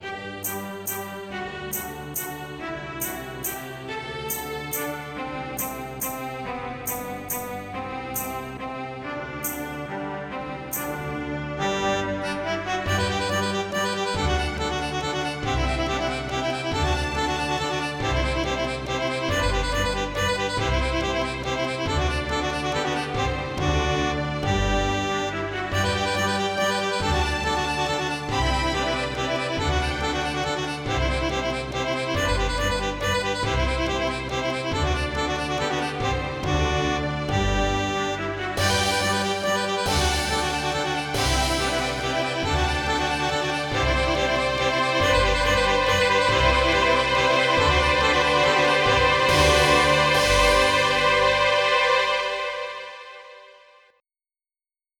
MIDI Music File
General MIDI